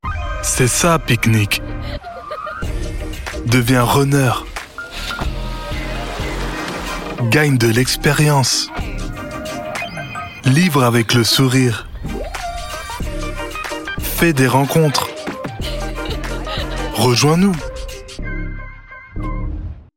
Confident, clear narration supporting PayPal’s brand communication.
0620Voice_confiant.mp3